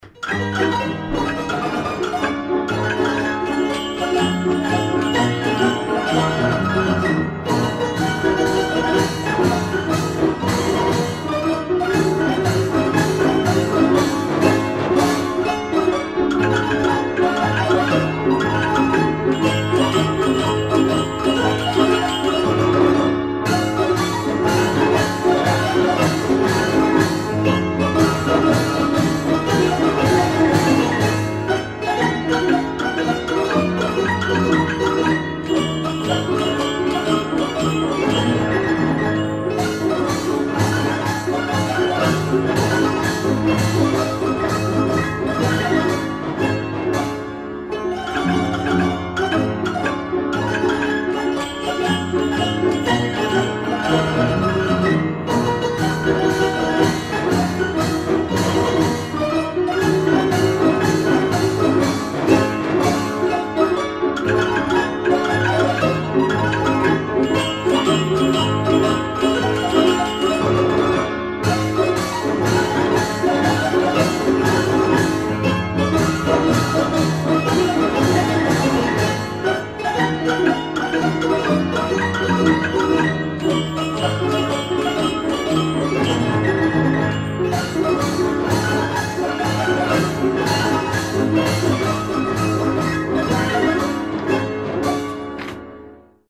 La polka Place blanche sur un piano mécanique
danse : polka